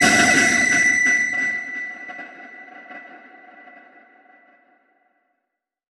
Index of /90_sSampleCDs/Transmission-X/One Shot FX
tx_fx_ouch.wav